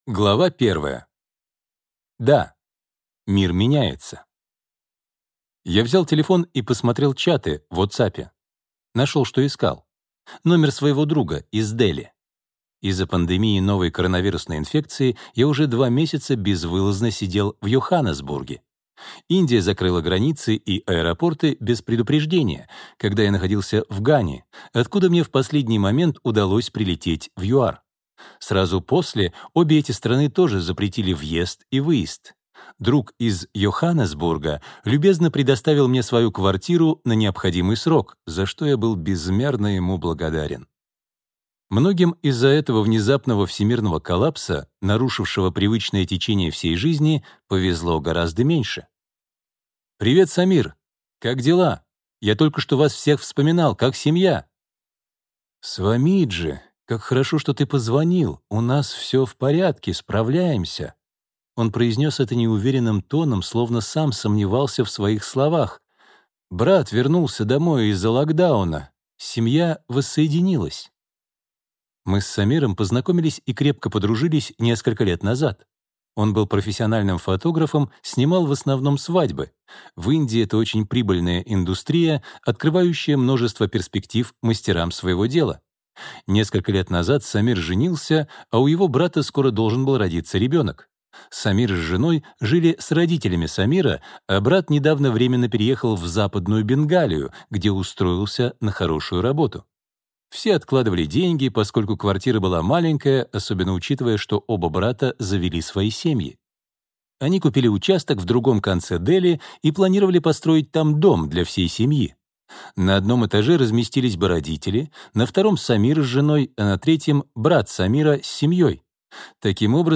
Аудиокнига Источник силы. Как найти ресурсы внутри себя и обрести спокойствие в меняющемся мире | Библиотека аудиокниг